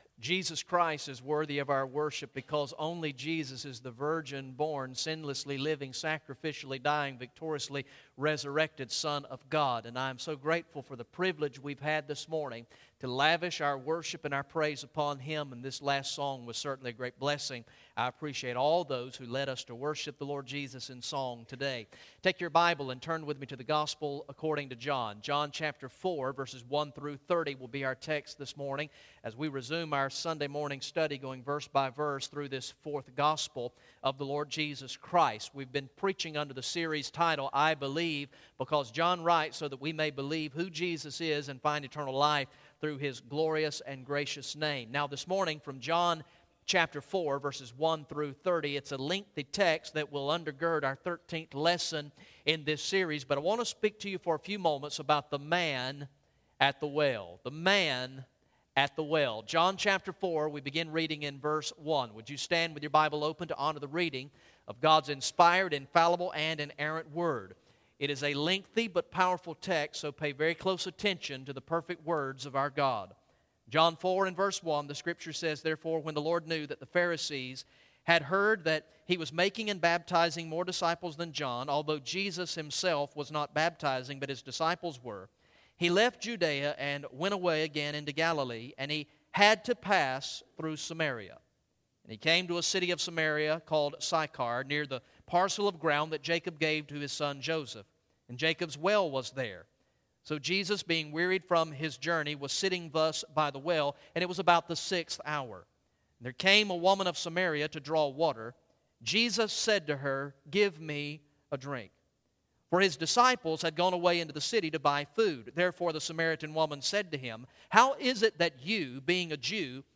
Message #13 from the sermon series through the gospel of John entitled "I Believe" Recorded in the morning worship service on Sunday, June 22, 2014